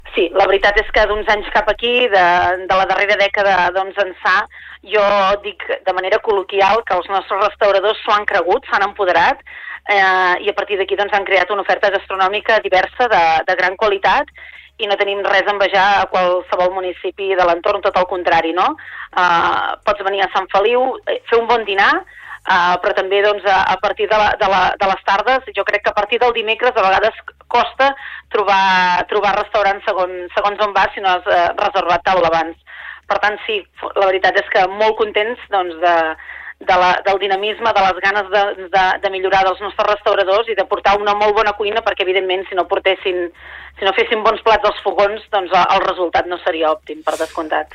Supermatí - entrevistes
Per parlar-nos d’aquestes dues propostes ens ha visitat la regidora de turisme de Sant Feliu de Guíxols, Núria Cucharero.